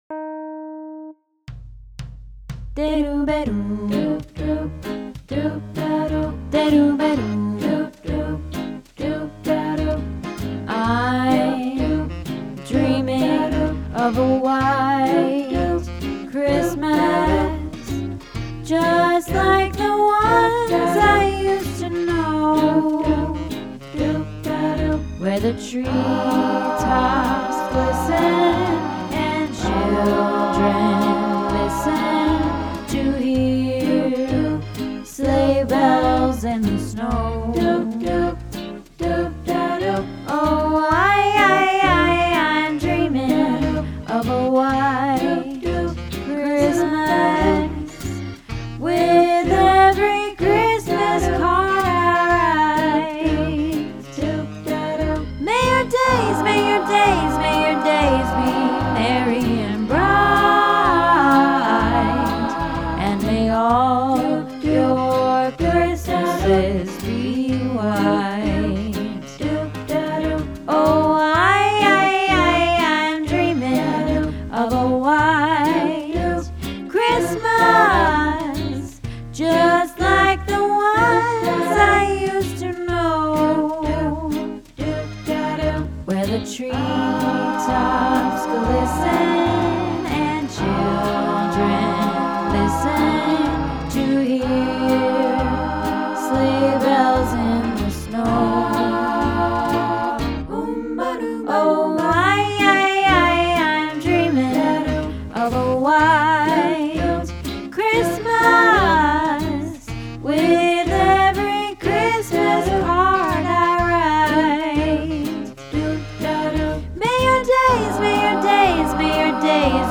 White Christmas - Practice